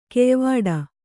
♪ keyvāḍa